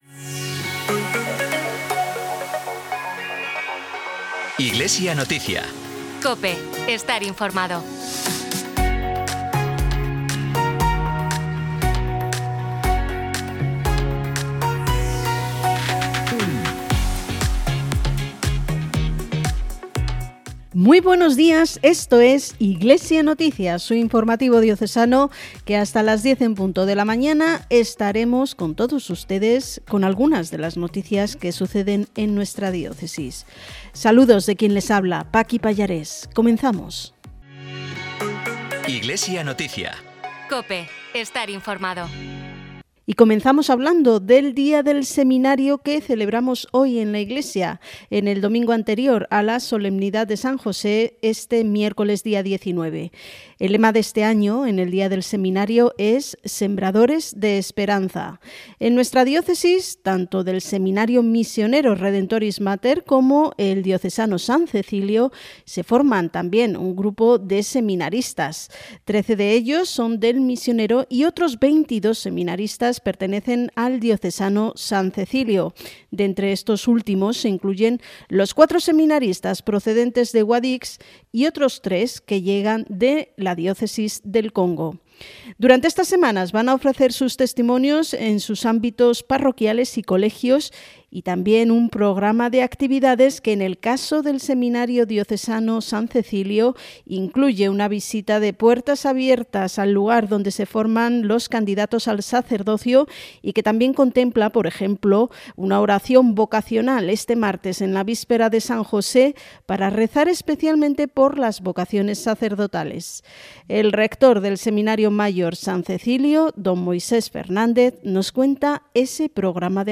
Programa emitido en COPE Granada y COPE Motril, el 16 de marzo de 2025.
Disponible en internet el informativo diocesano emitido el domingo 16 de marzo, en COPE Granada y COPE Motril, con la actualidad de la Iglesia en Granada. En esta ocasión, abordamos, entre otros temas, el Día del Seminario, que se celebraba el domingo día 16, con motivo de la Solemnidad de San José, que, por ser día laborable, se ha adelantado al domingo anterior.